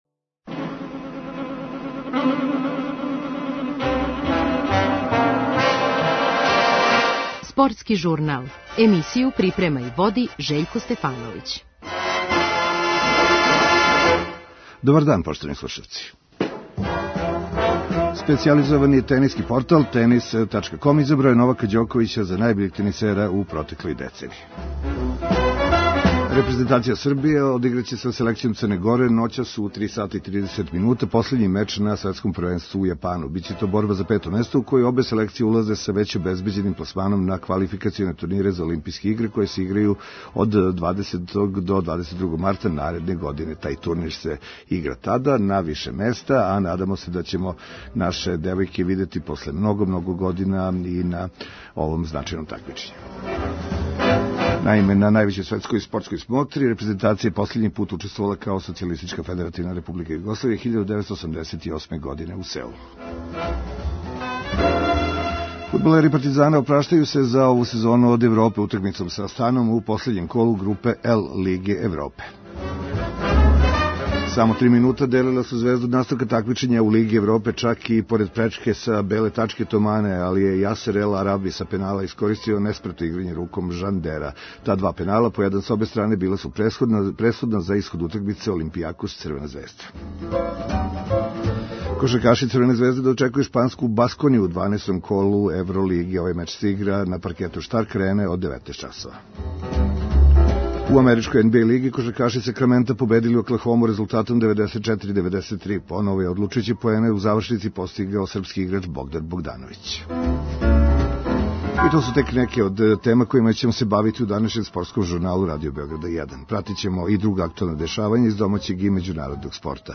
Слушаћемо утиске актера синоћње утакмице, играчи и навијачи окрећу се домаћем шампионату, уз нескривену жељу да догодине поново Звезда буде саставни део групног такмичења Л